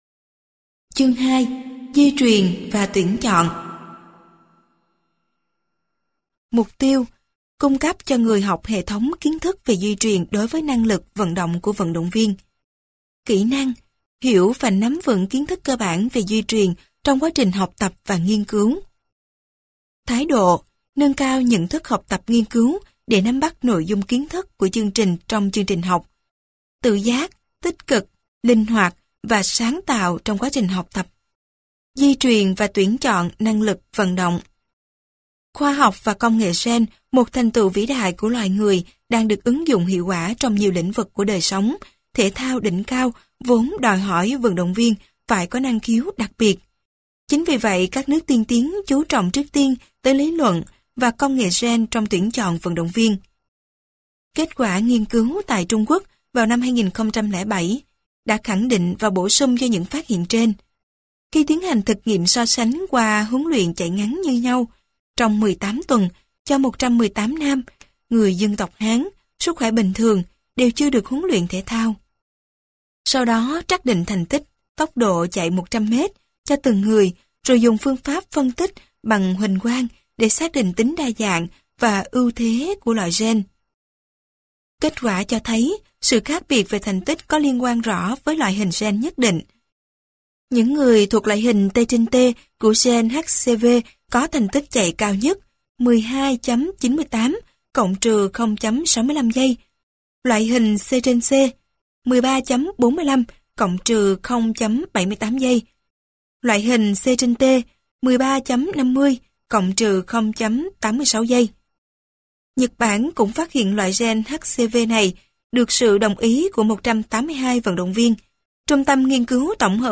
Sách nói Tài liệu Giảng dạy môn Tuyển chọn vận động viên thể thao - Sách Nói Online Hay